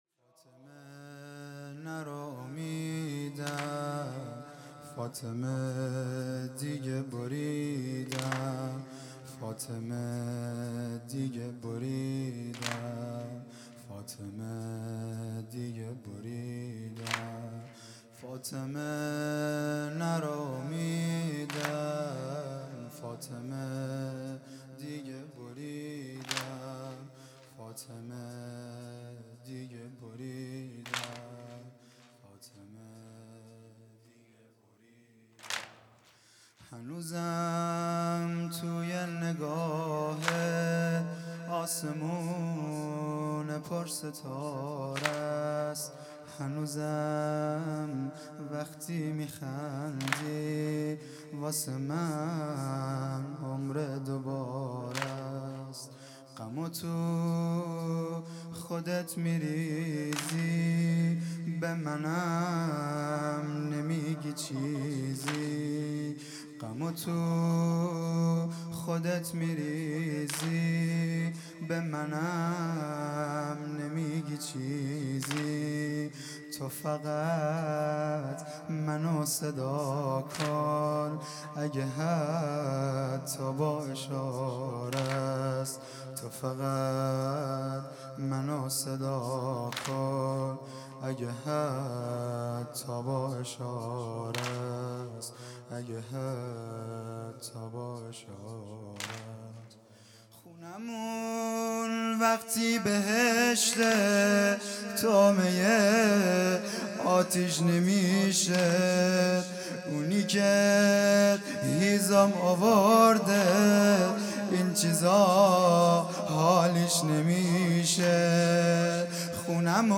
شهادت حضرت زهرا (س) | ۱۰ اسفند ۱۳۹۵